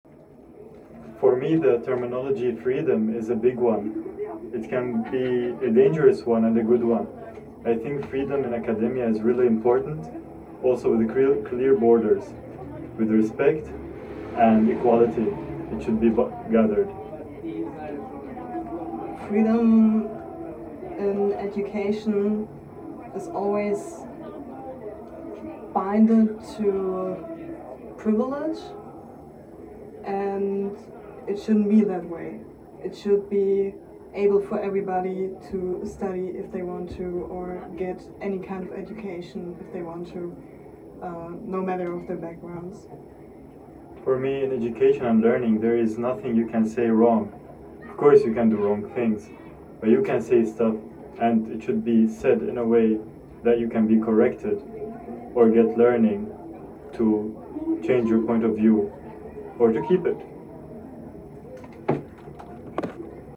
Off University / Lange Nacht der Wissenschaften @ Berlin